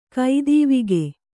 ♪ kaidīvige